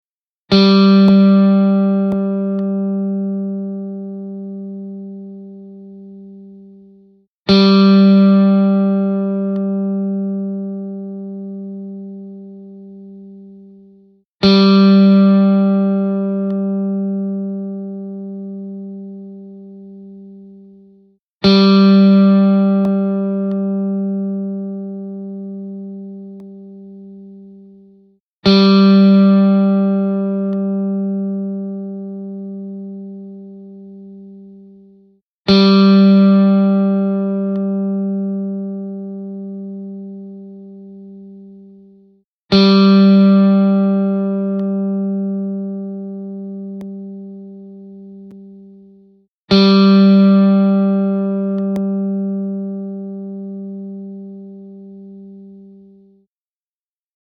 Hier die Stimmtöne:
• 3 G-Saite
3-G-String.mp3